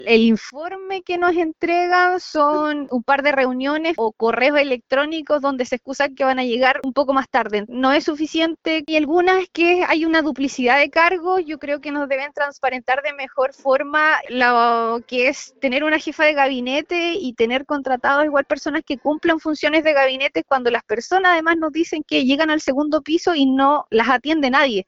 concejala-evelyn-chavez.mp3